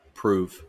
IPA/ˈpruːv/ wymowa amerykańska?/i